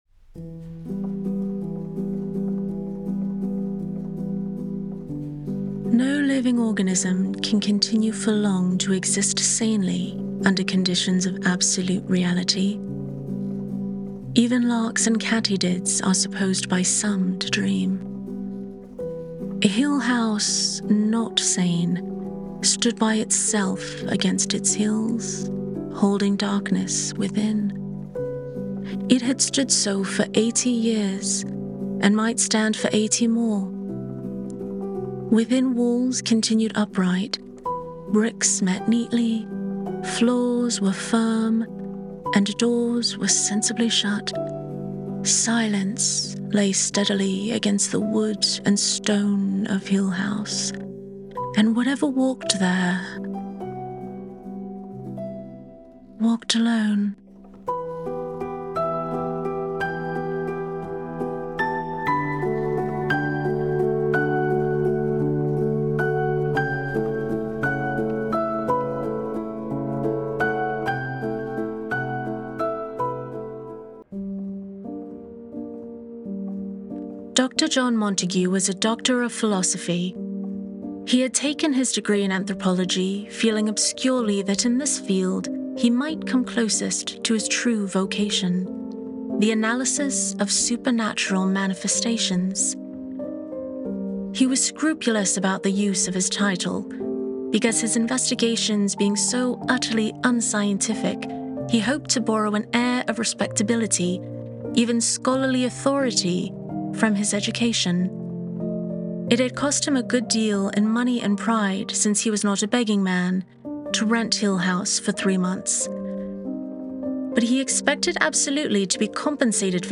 Playing around with British accents (don't judge me), moody music (seriously, don't judge me!), and GarageBand (okay, you can judge me for this one).